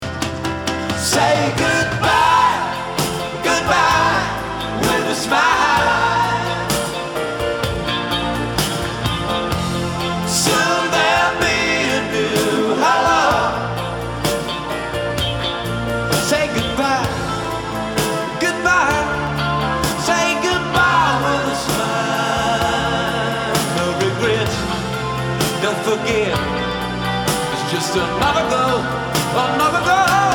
Lead Voc. - Mixec Choir - Chords
key: F-Major